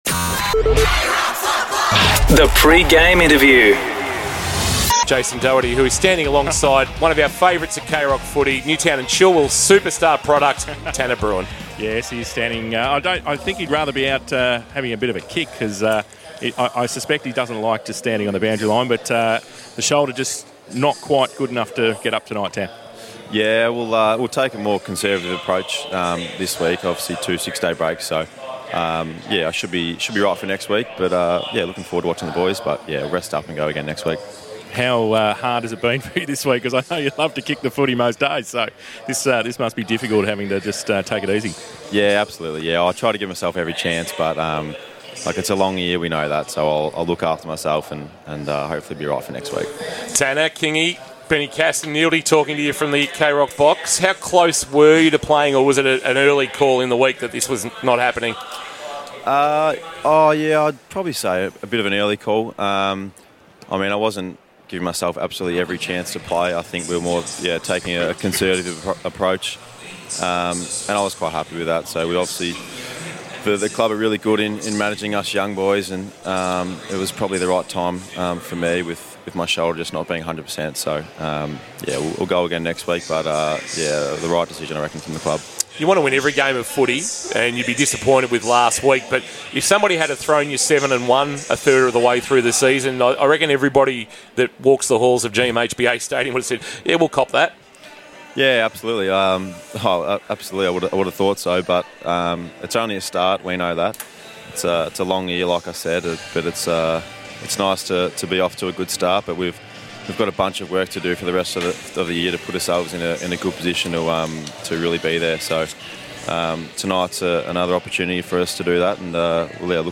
2024 - AFL - Round 10 - Geelong vs. Port Adelaide: Pre-match interview